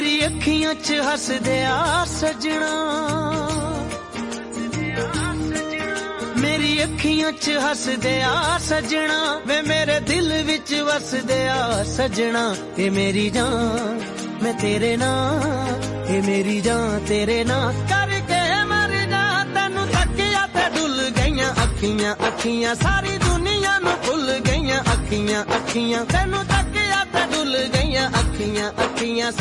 Sad Ringtones